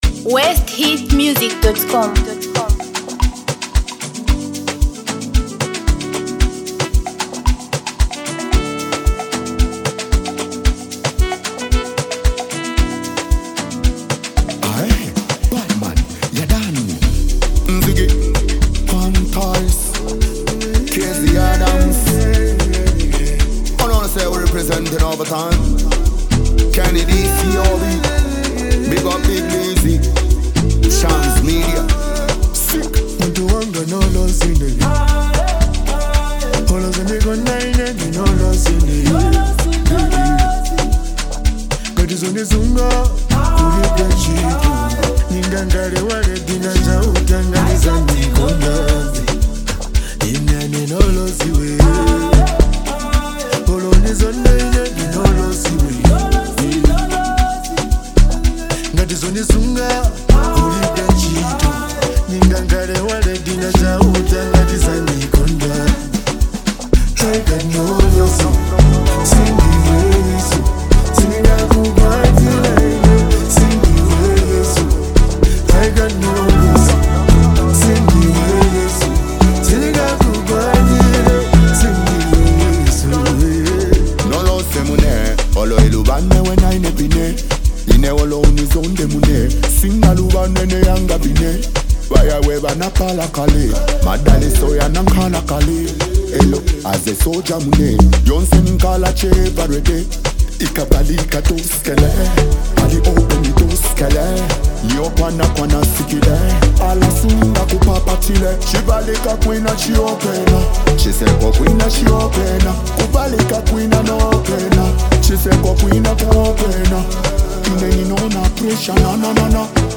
Zambia Music